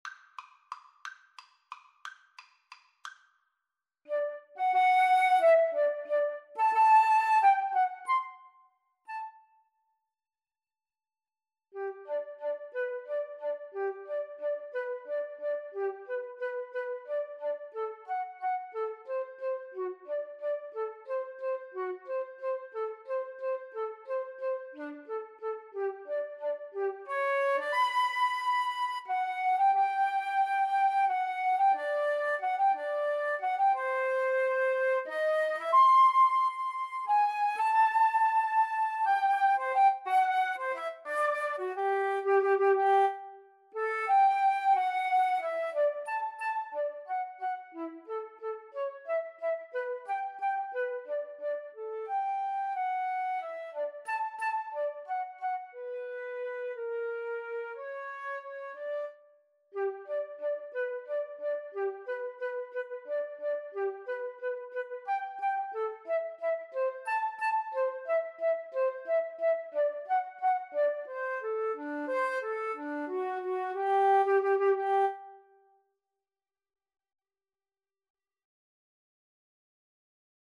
~ = 180 Tempo di Valse
Classical (View more Classical Flute Duet Music)